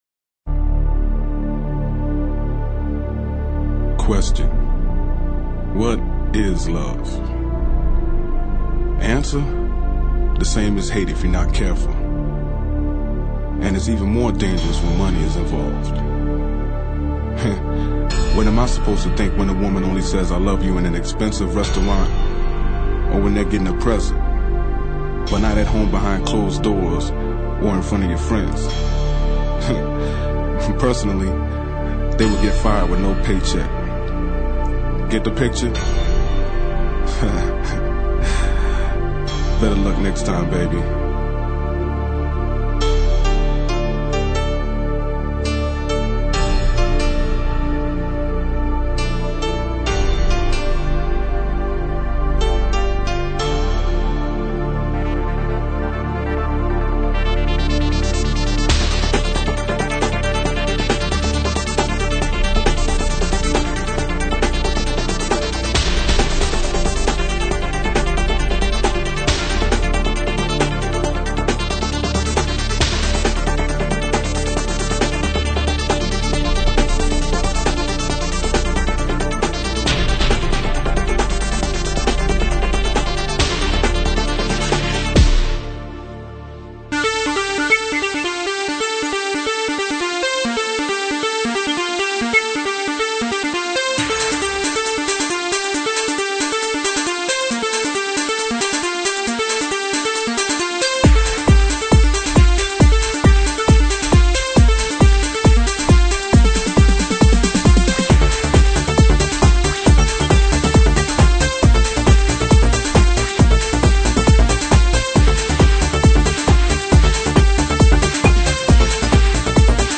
Un mix 100% generation 90 !